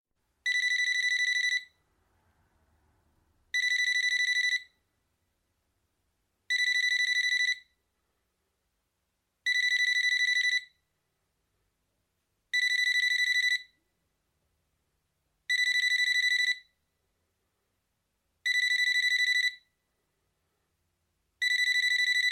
digital-tone_24889.mp3